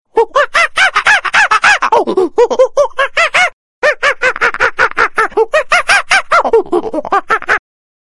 جلوه های صوتی
دانلود صدای حیوانات 19 از ساعد نیوز با لینک مستقیم و کیفیت بالا